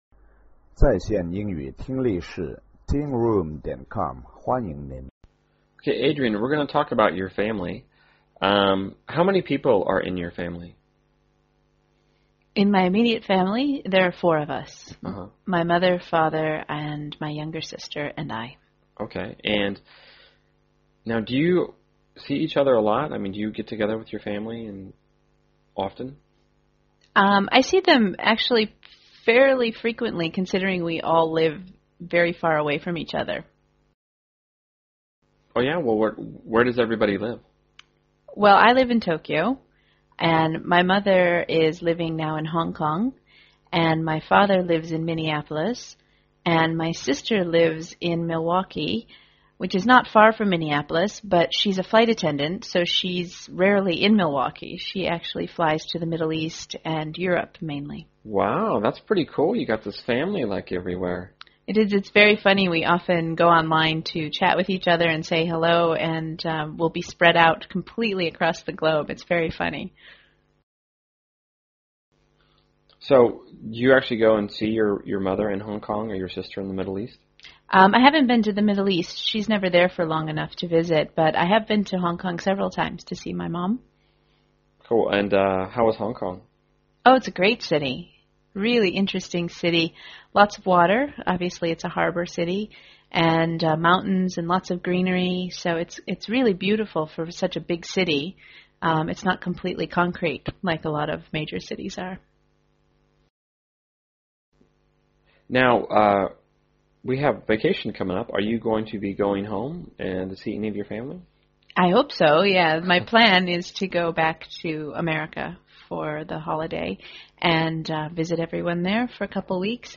在线英语听力室英文原版对话1000个:695 The Global Family的听力文件下载,原版英语对话1000个,英语对话,美音英语对话-在线英语听力室